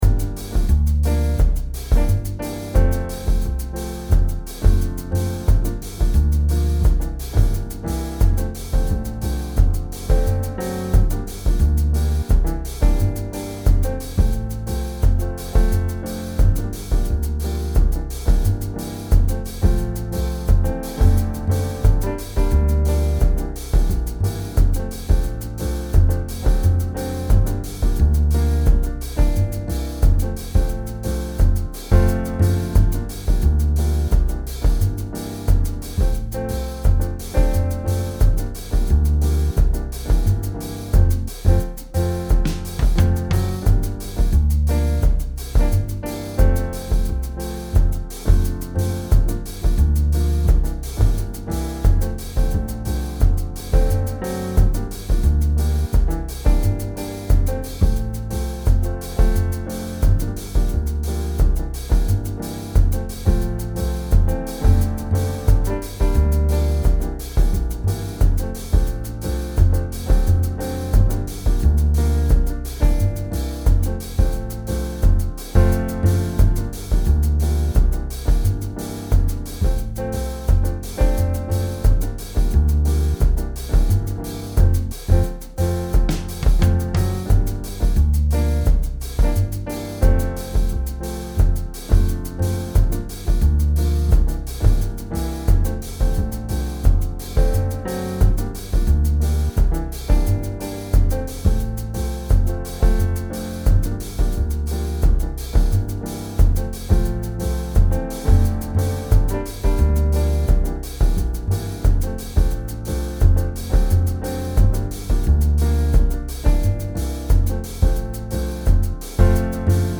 Calypso-ii-V-I-in-F.mp3